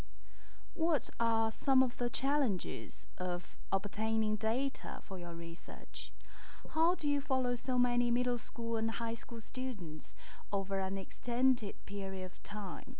Vocab The One Child Policy A British journalist is interviewing a Chinese social scientist.